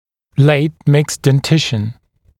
[leɪt mɪkst den’tɪʃn][лэйт микст дэн’тишн]поздний сменный прикус; поздняя стадия смены зубов